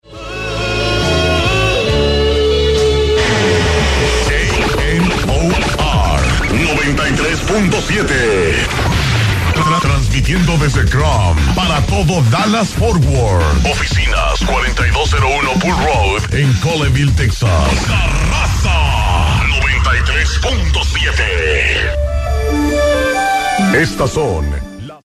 KNOR Top of the Hour Audio:
This FM Regional Mexican radio station is licensed by the FCC to ESTRELLA RADIO LICENSE OF DALLAS LLC in Krum, Texas.